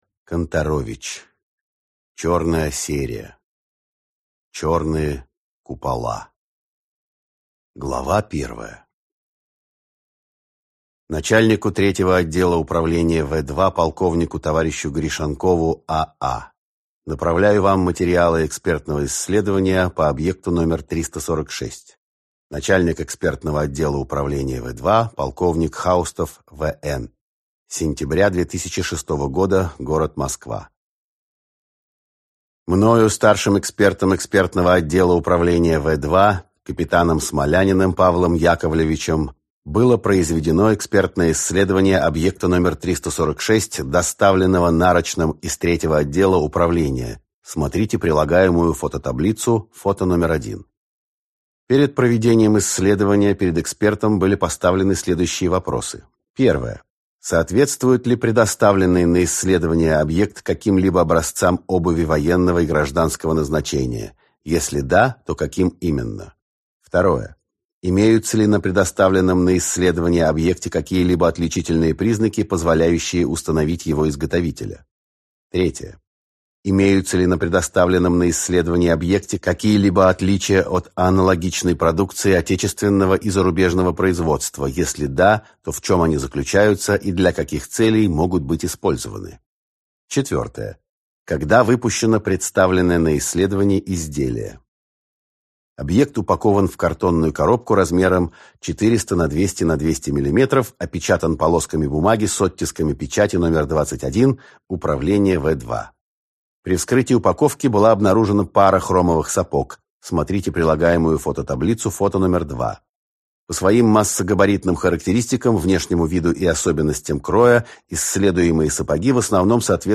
Аудиокнига Черные купола. Выстрел в прошлое | Библиотека аудиокниг